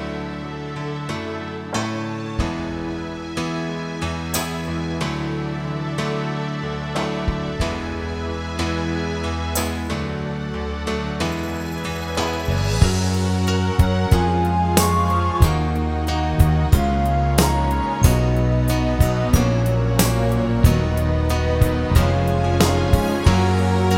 No Backing Vocals Soundtracks